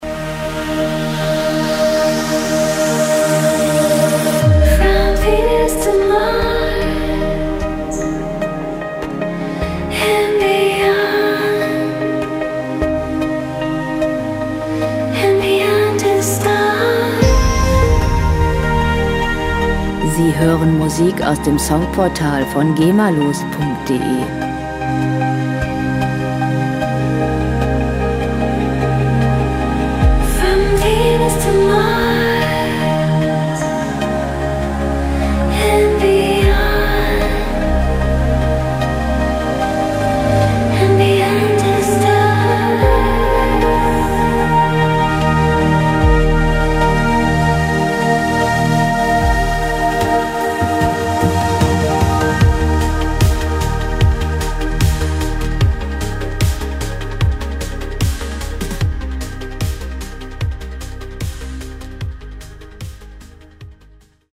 • Space Pop